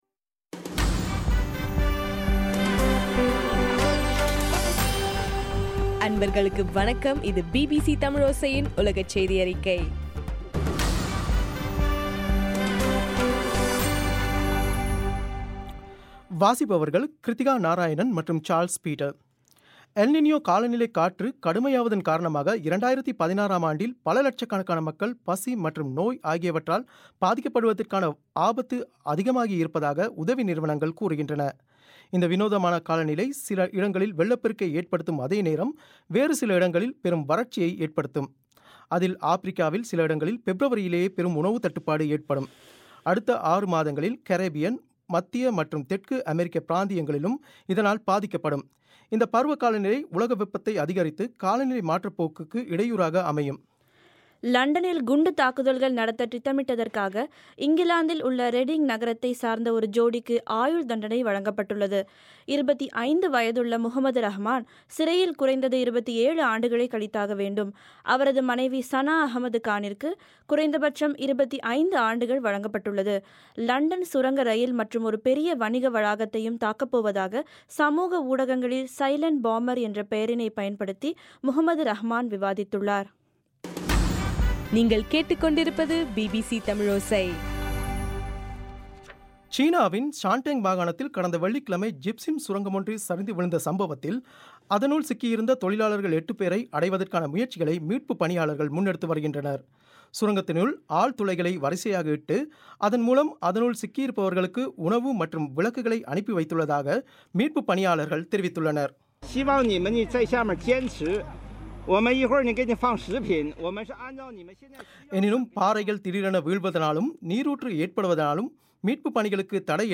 பிபிசி தமிழோசை உலகச் செய்தியறிக்கை: டிசம்பர் 30